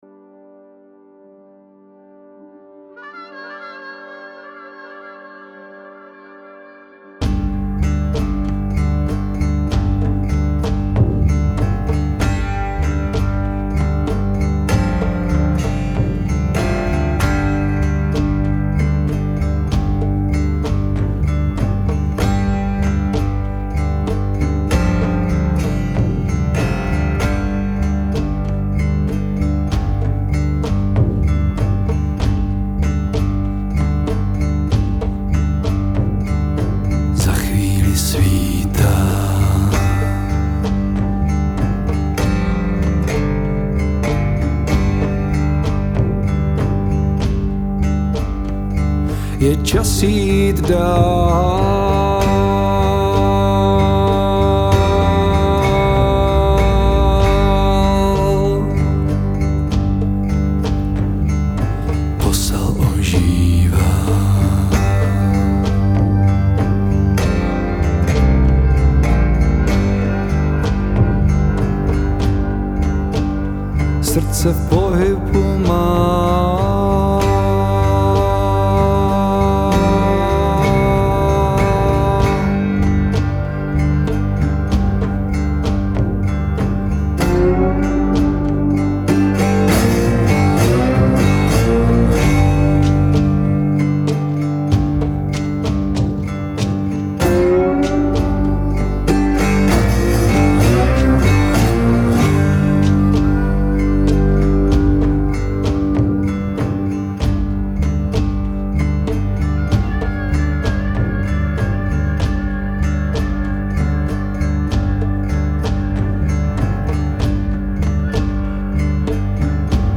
cigarbox guitar, zpěv, české texty
Tvoří emočně silné písně, s vlivy blues i world music.
Sakrální blues.